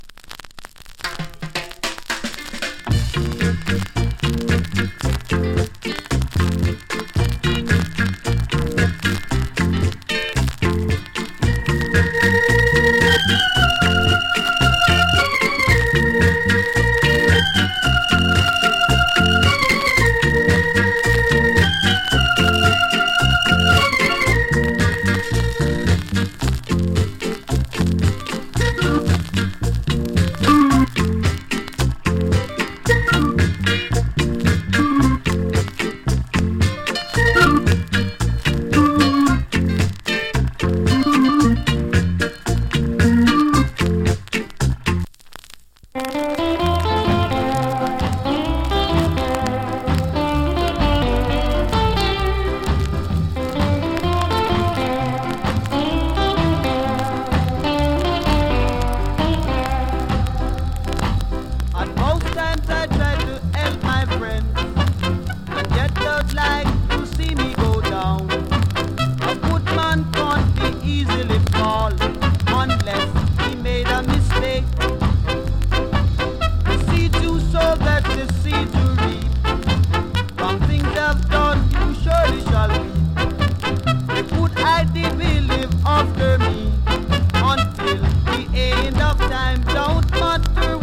チリ、パチノイズ多数有り。
RARE ORGAN INST ! & NICE SKA VOCAL !